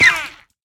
sounds / mob / dolphin / hurt3.ogg
hurt3.ogg